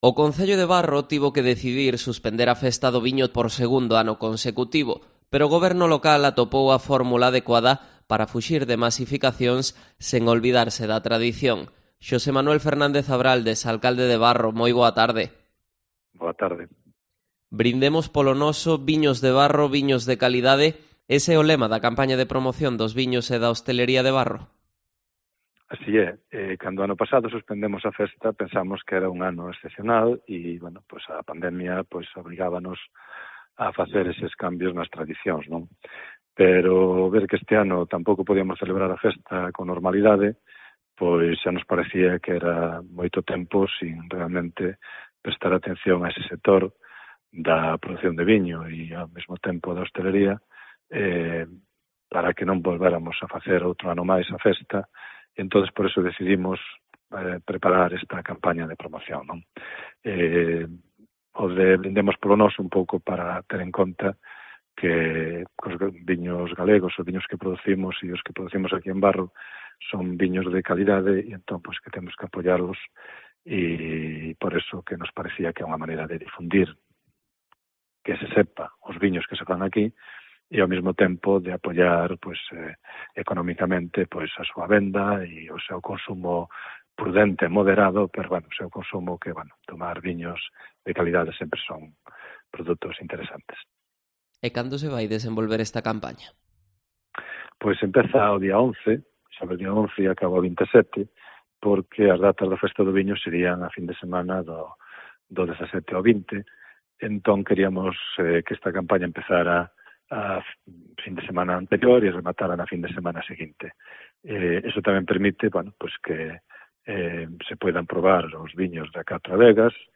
Entrevista a Xosé Manuel Fernández Abraldes, alcalde de Barro